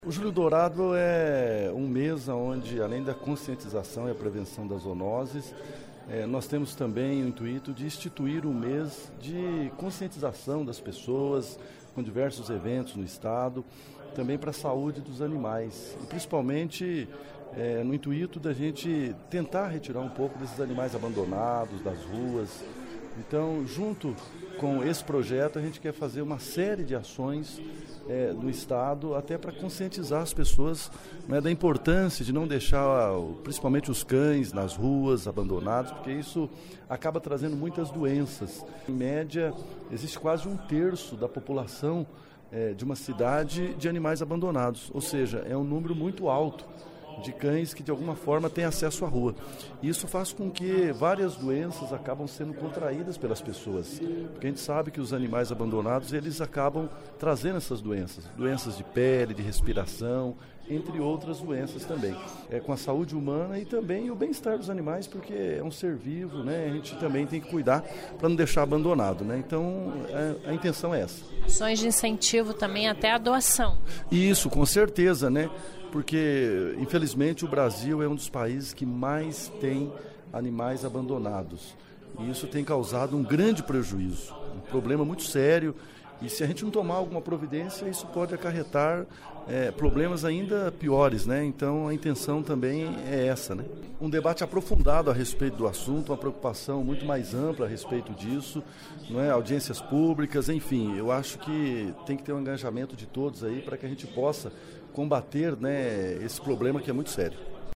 Ouça entrevista, onde o deputado Cobra Repórter (PSD), fala sobre a importância do projeto de lei de sua autoria, que institui o "Julho Dourado", mês de reflexão e promoção de eventos sobre a saúde dos animais de rua e animais domésticos de estimação e a importãncia da prevenção de zoonoses.